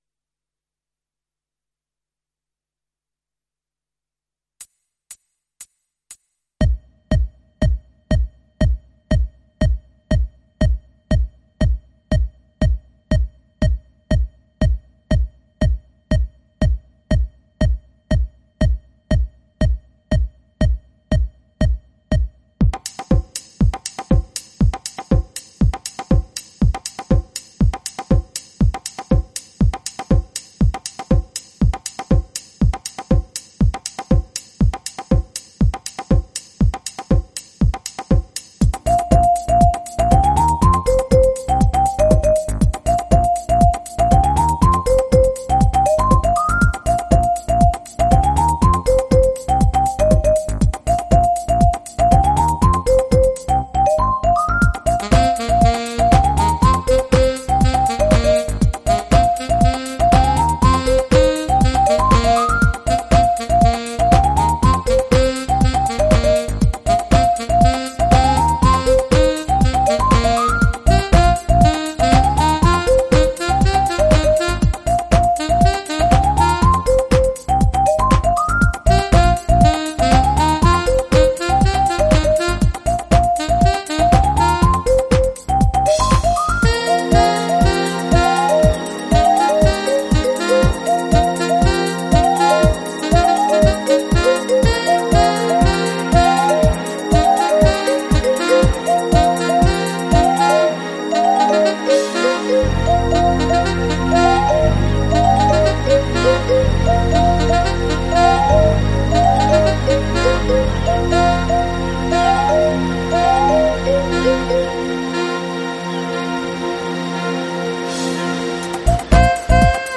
Professional musical transcription (backing track)